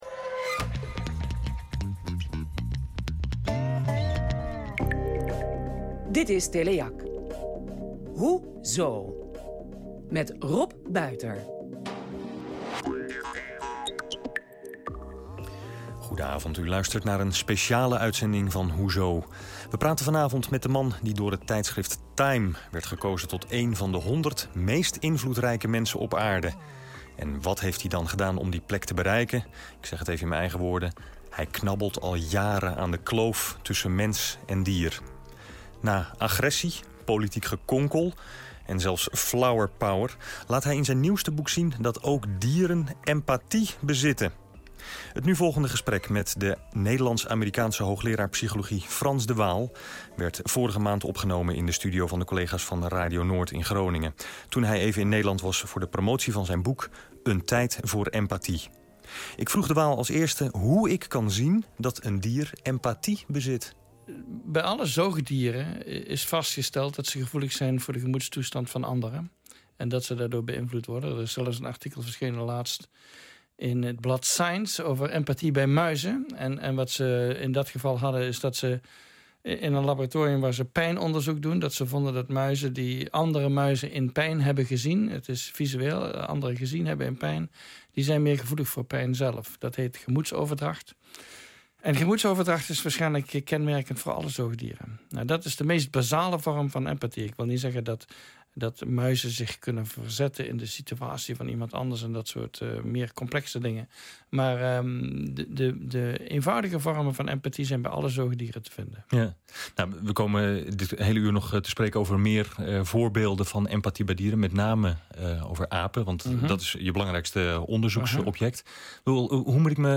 Radio interview with Hoezo? a Teleac program (Dutch).